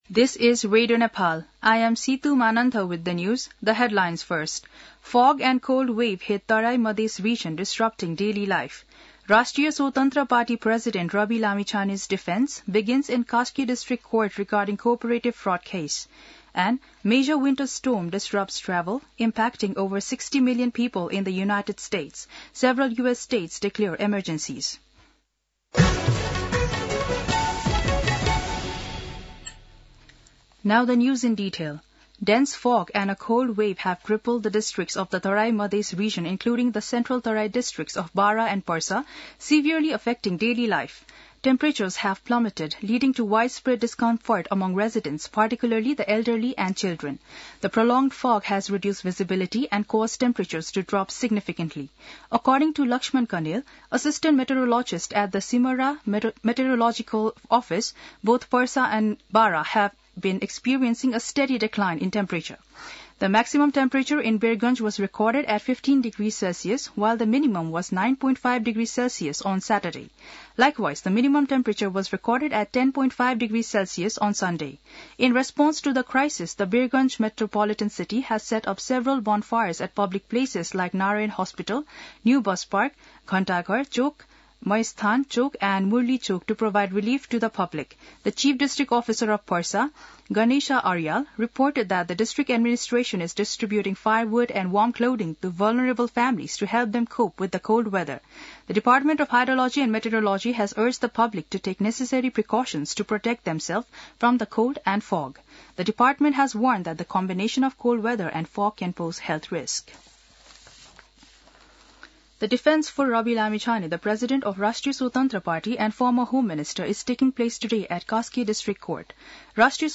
दिउँसो २ बजेको अङ्ग्रेजी समाचार : २३ पुष , २०८१
2-pm-news-.mp3